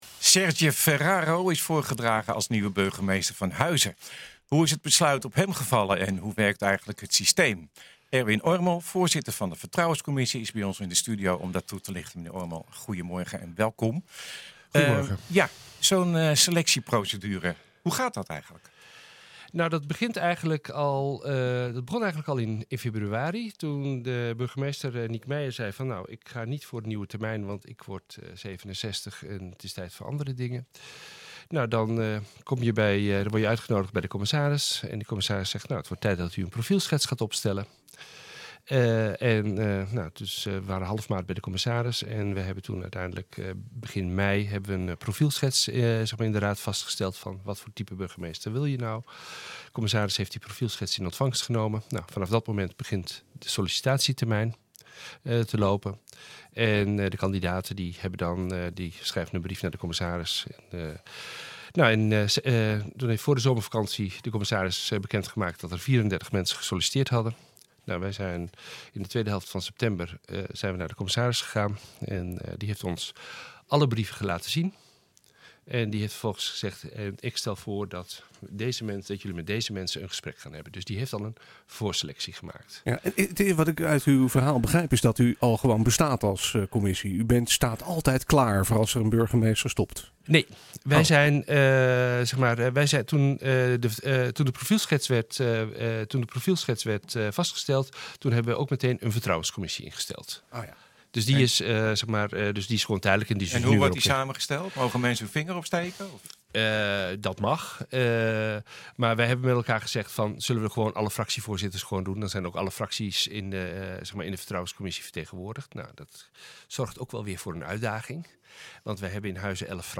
Hoe is het besluit op hem gevallen en hoe werkt eigenlijk het systeem? Erwin Ormel, voorzitter van de vertrouwenscommissie, is bij ons in de studio om dit toe te lichten.